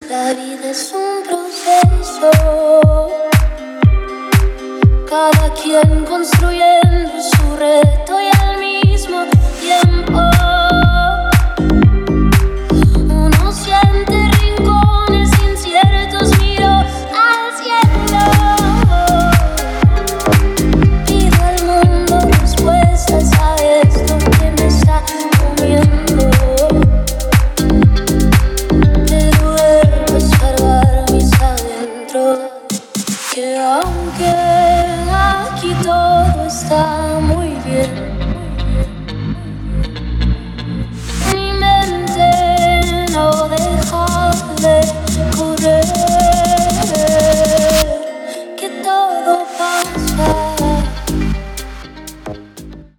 • Качество: 320, Stereo
Стиль: deep house , electronic.